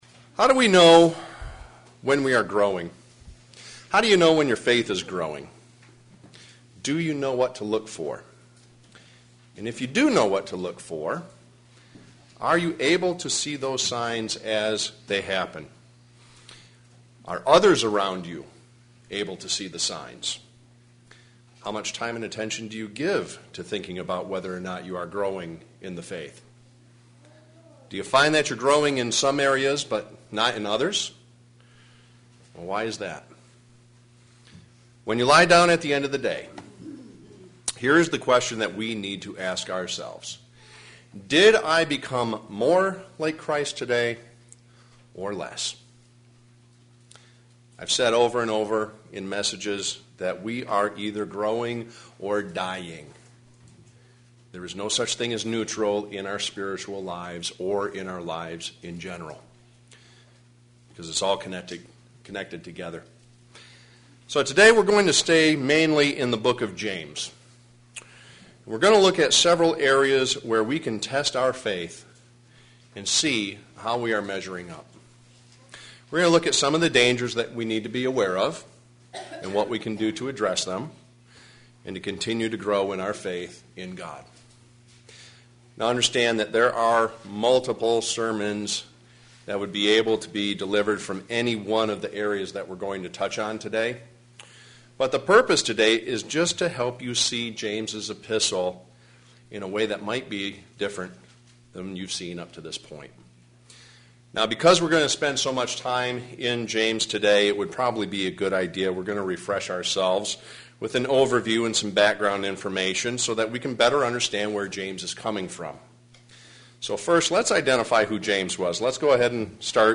Today, we will look in a New Testament book for wisdom for Christianity. sermon Studying the bible?